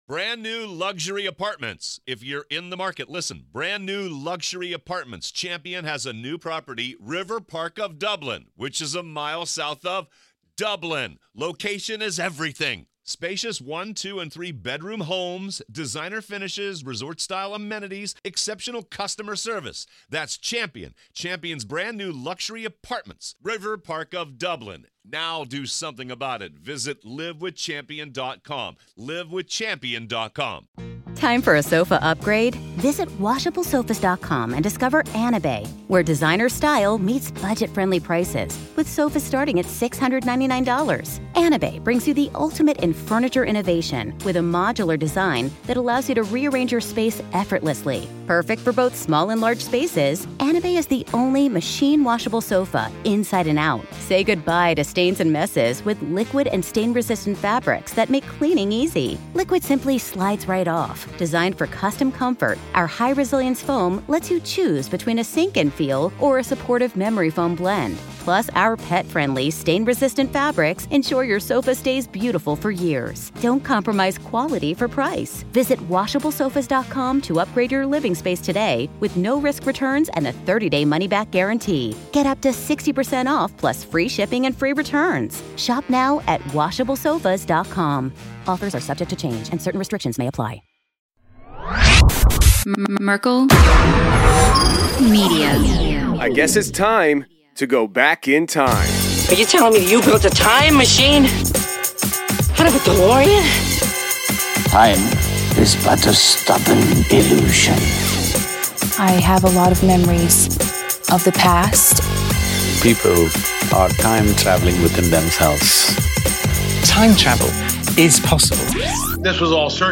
hold an open conversation about these topics, and how they relate to every human on planet Earth today.